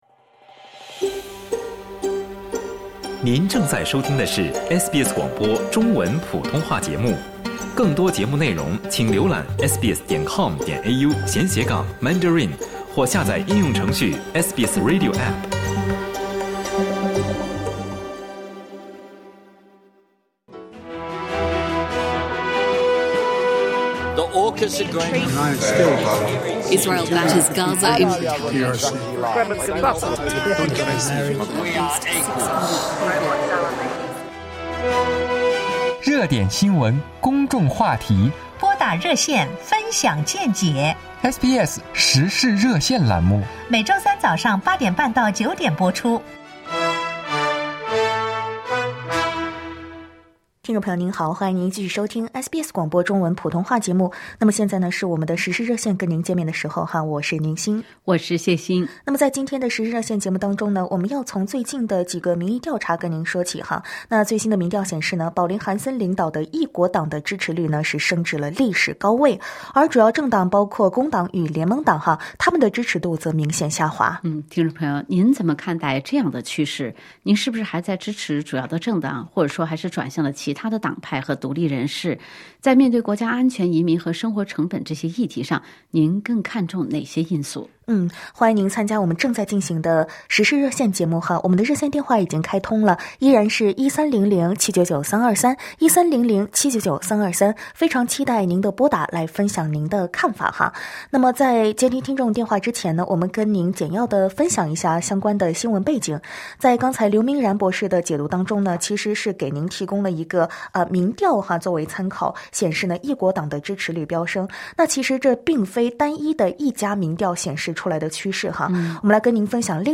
热线听众仅代表其个人观点，不代表本台立场，仅供参考。